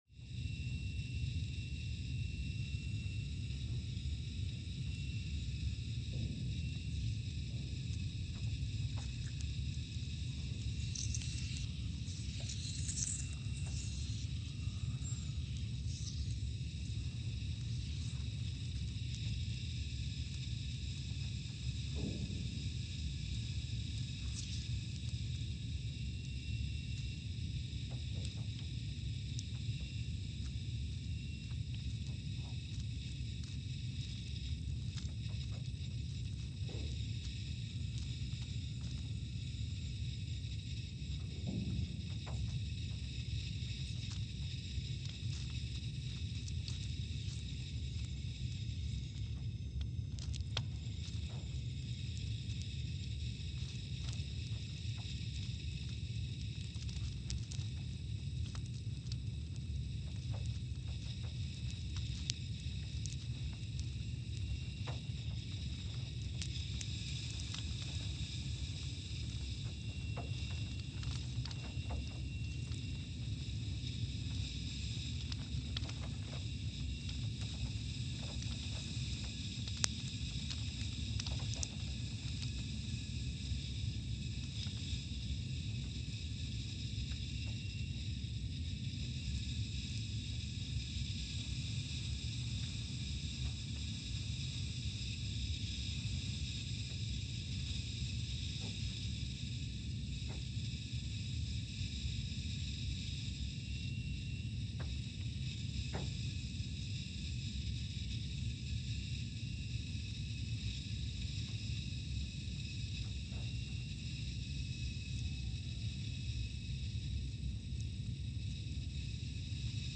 Scott Base, Antarctica (seismic) archived on June 24, 2021
Sensor : CMG3-T
Speedup : ×500 (transposed up about 9 octaves)
Loop duration (audio) : 05:45 (stereo)
SoX post-processing : highpass -2 90 highpass -2 90